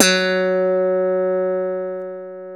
KW FUNK  F#3.wav